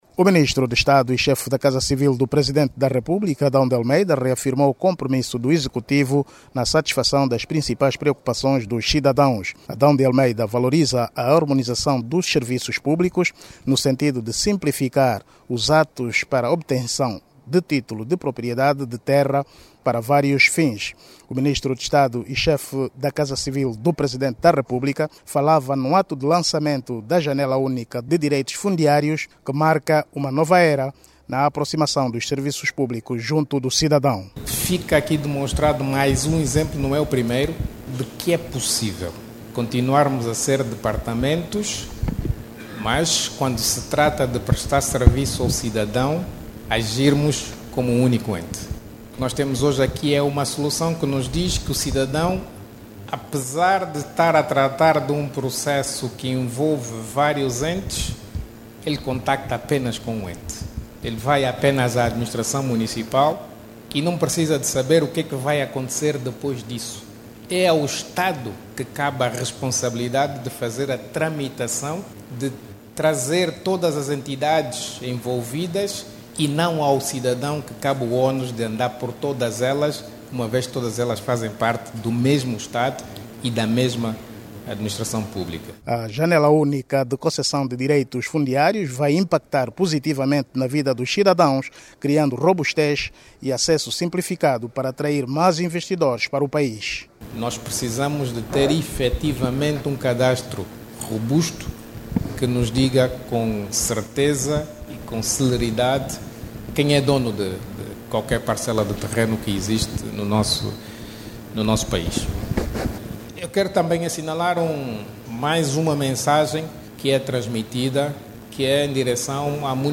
As declarações foram feitas por Adão de Almeida durante a inauguração da Janela Única de Concessão de Direitos Fundiários, uma plataforma enquadrada no projeto de modernização administrativa.